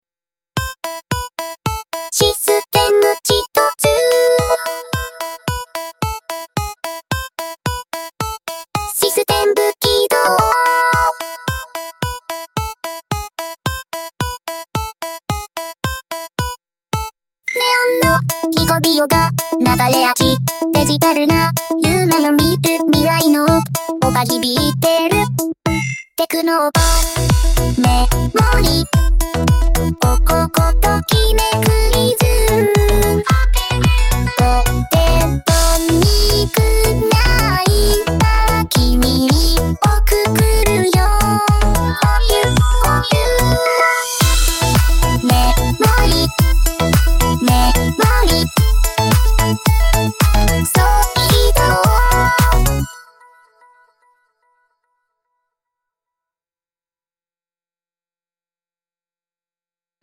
実際に「80年代っぽいテクノポップを作って」というプロンプトで試してみたところ、ボコーダー風な歌声や、シンセサイザーのキラキラした音色やリズムマシン的なビートが特徴的な楽曲が生成されました。